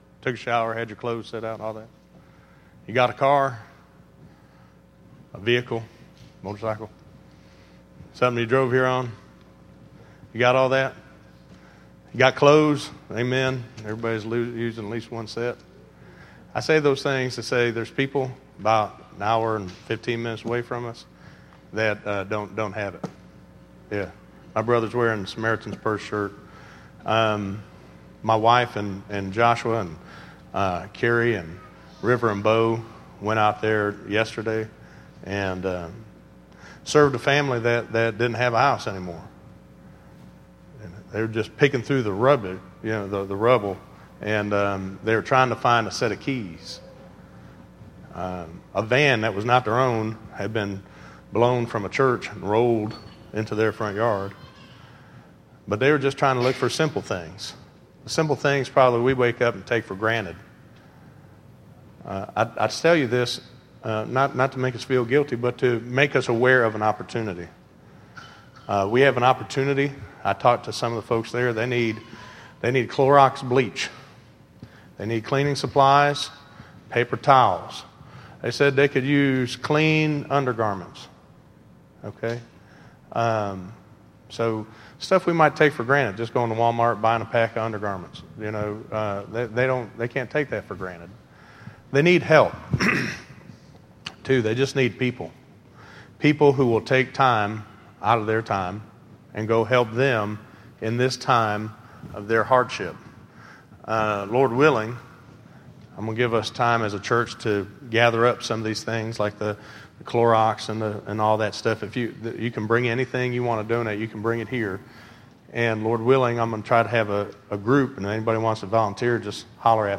Listen to What would Jesus say to you - 05_04_2014_Sermon.mp3